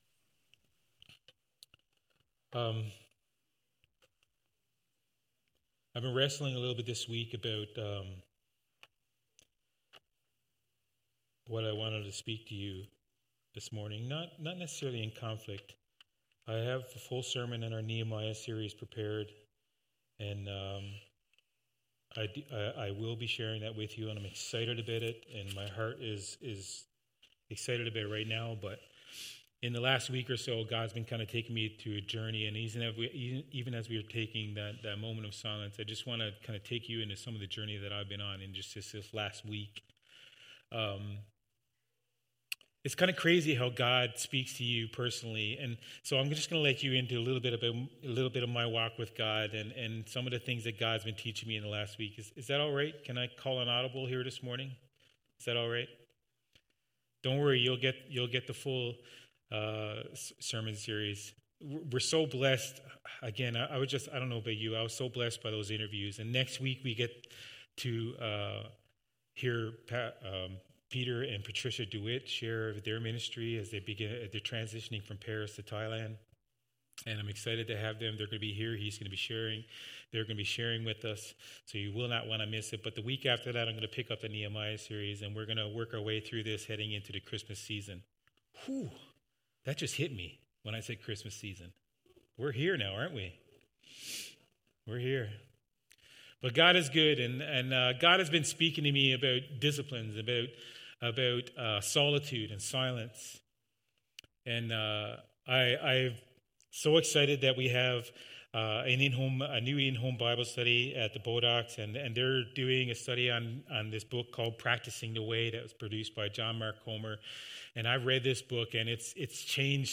Sermons | Warden Full Gospel Assembly